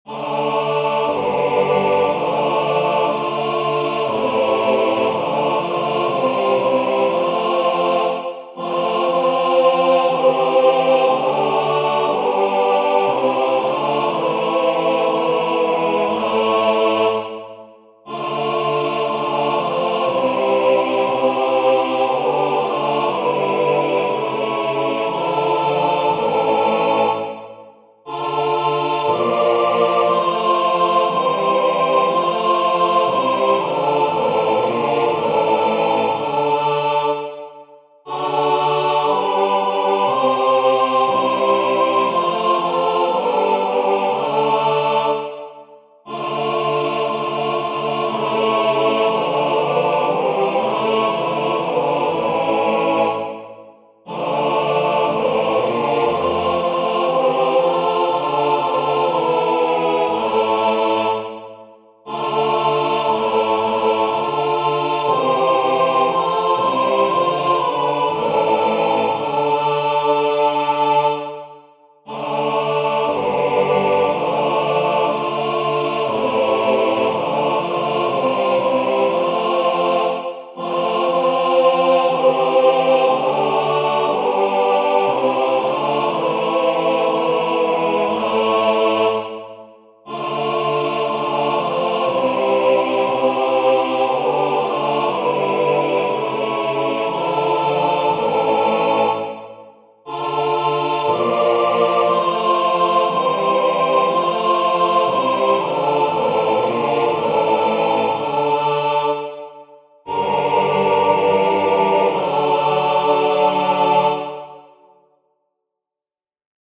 Vers 1: eerste helft mf, tweede helft (dat is pagina 2) p.
Meezingen